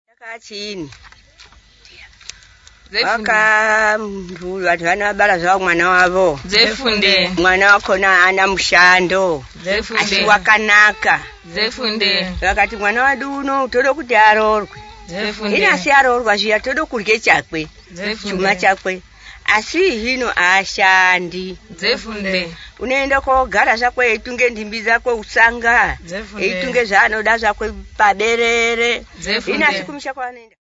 Folk music--Africa
Field recordings
sound recording-musical